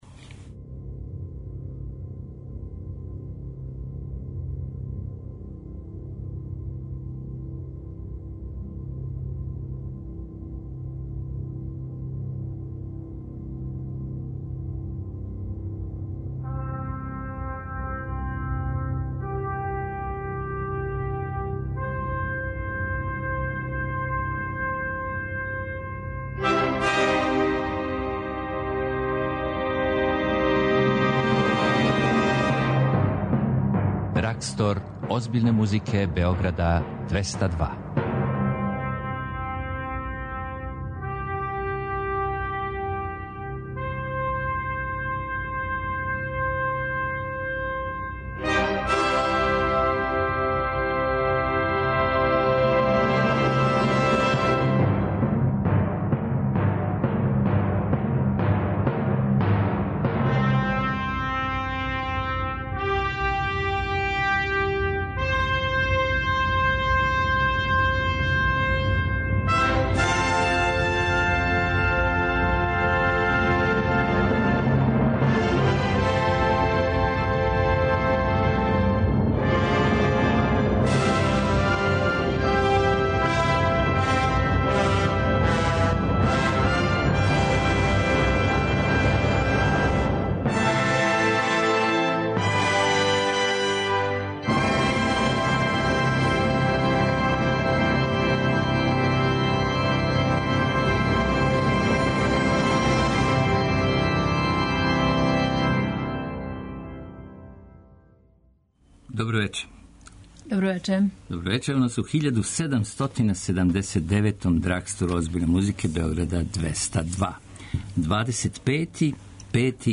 Култна емисија Београда 202 која промовише класичну музику.
Из вечерашње листе издвајамо концерте за харфу В. А. Моцарта и мање познатог француског композитора који се зове Франсоа Адриан Боалдју, као и Фореову Павану у аранжману за харфу и необични инструмент теремин!